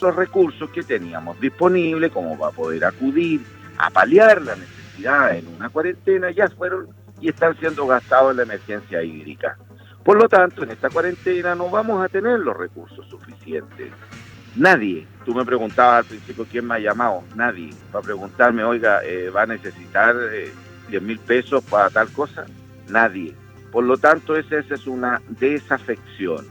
En conversación con Primera Hora de Radio Sago, la autoridad edilicia indicó que gran parte del presupuesto del municipio se ha ido para abastecer de agua a familias y a sus animales y que para enfrentar esta nueva cuarentena requiere de recursos para ir en ayuda de personas que no podrán trabajar el día a día.